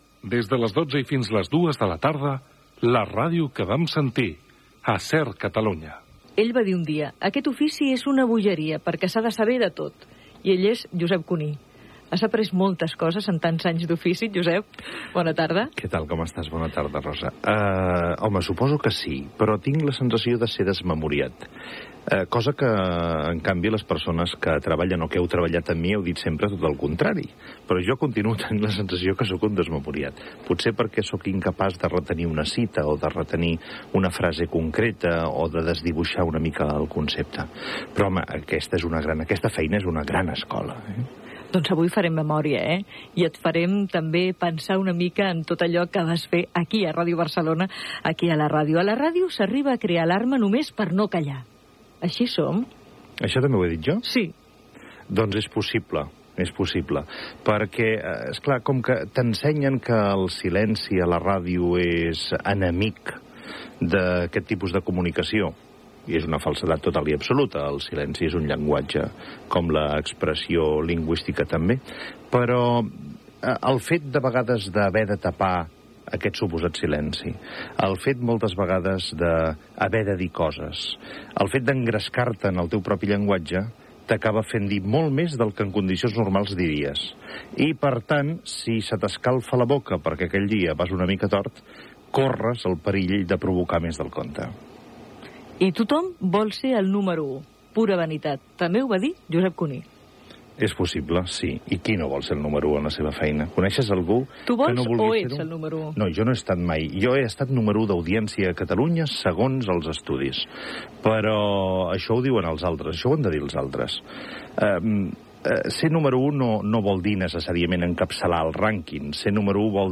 Indicatiu de l'emissora i del programa. Entrevista a Josep Cuní sobre el seu pas per Ràdio Barcelona.
Divulgació